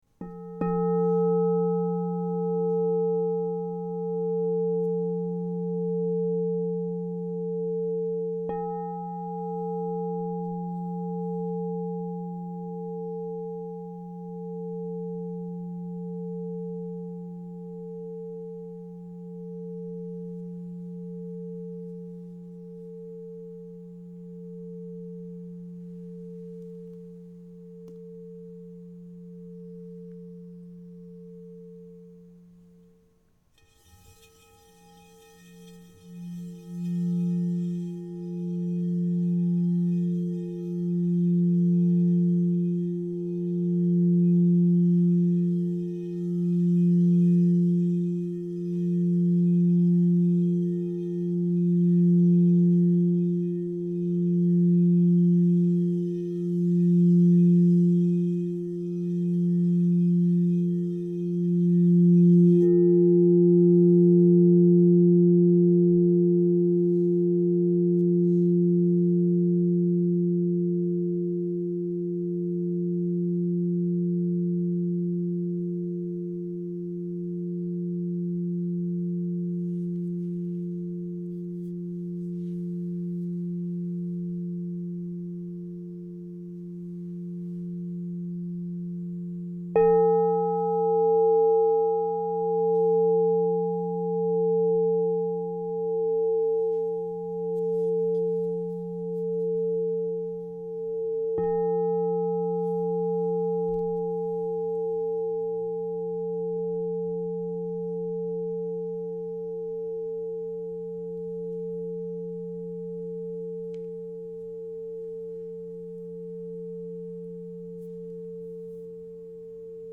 Pink Ocean Gold, Frosted Rim 12" F 0 Crystal Tones Singing Bowl
Genuine Crystal Tones® True Tone alchemy singing bowl.
With its expansive 12" form and frosted rim, this sacred instrument offers a velvety, flowing voice that wraps the listener in warmth. Tuned to a Perfect True Tone F note (0 cents), it delivers a remarkably centered and reliable resonance—an immaculate tonal foundation ideal for deep sound healing, soothing coherence, and serene heart alignment.
Its shimmering harmonics encourage tender release, heartfelt connection, and a relaxed return to harmony with self and spirit.
440Hz (TrueTone)